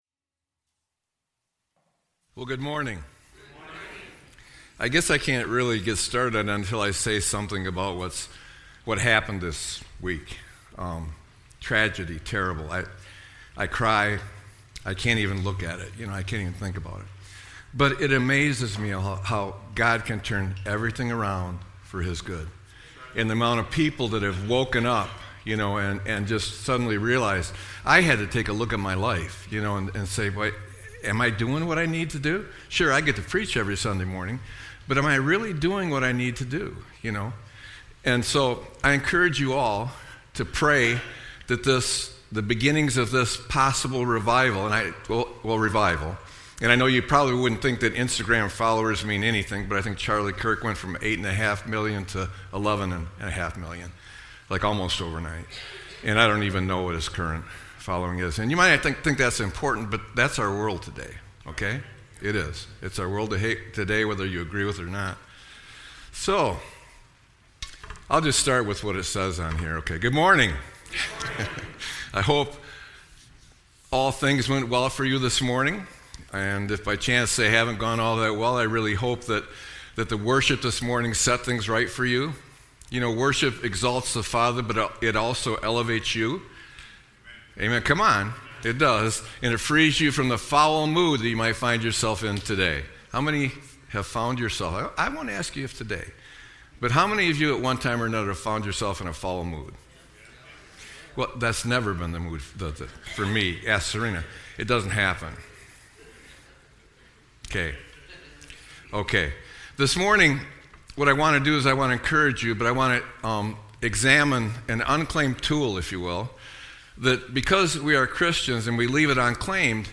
Sermon-9-14-25.mp3